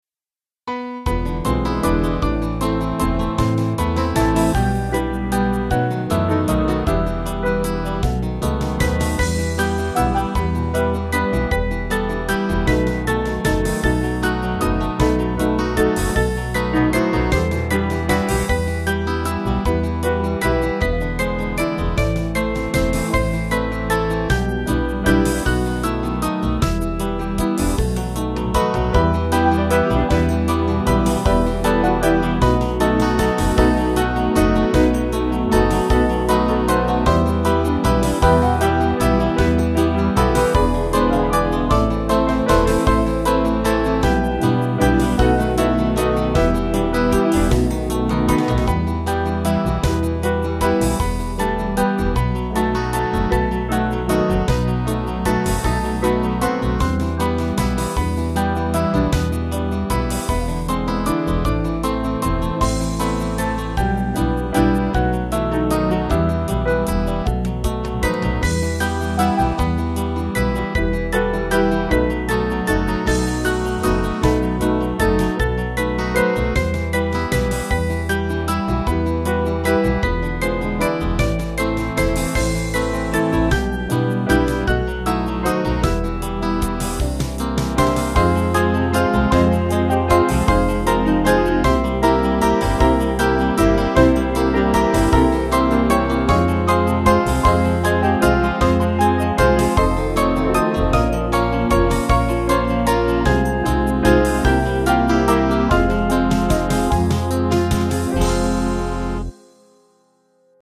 Small Band
(CM)   5/Em-Fm 282.7kb